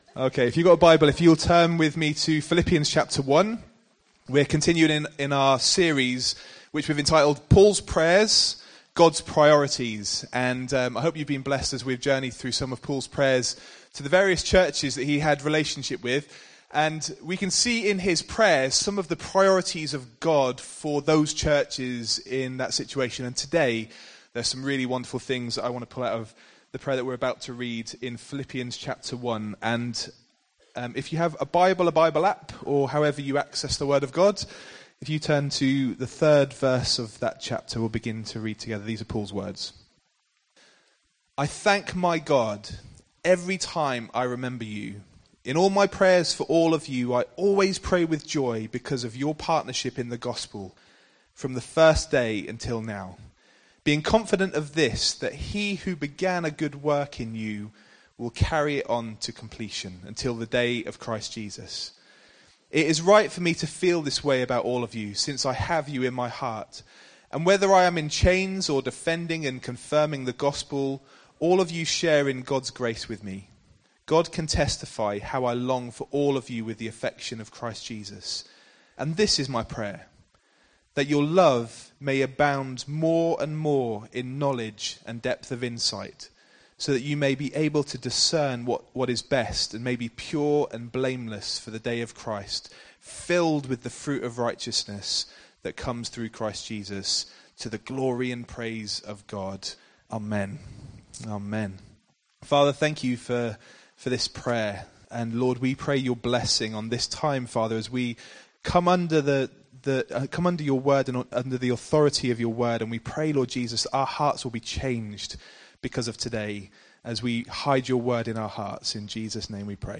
Nov 24, 2019 Partnership in the Gospel MP3 SUBSCRIBE on iTunes(Podcast) Notes Sermons in this Series Growing in our faith and producing fruit should be the aim of all mature Christians.